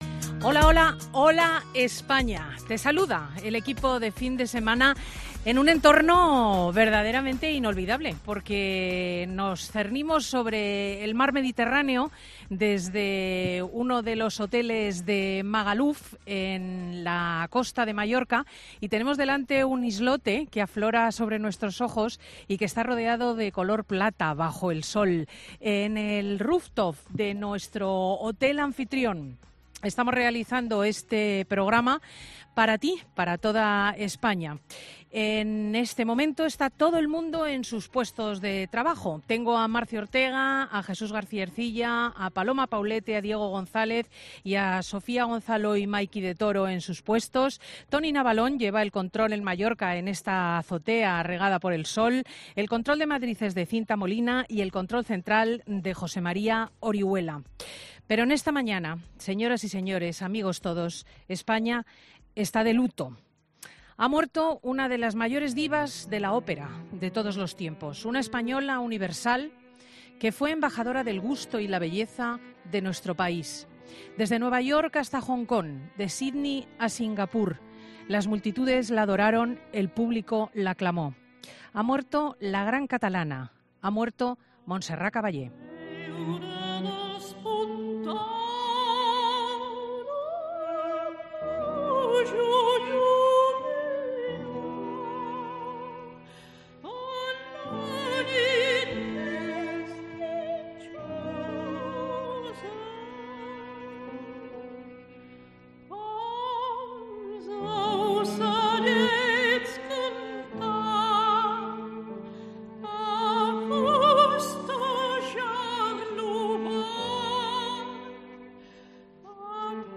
Montserrat Caballé falleció el pasado 6 de octubre y Cristina López Schlichting le dedicaba unas emocionantes palabras llenas de cariño hacia la irrepetible soprano catalana.